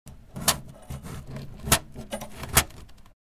ラトリング（rattling、ビビリ音）のないこと、ハム（hum、弦鳴り）のない状態で
strings.MP3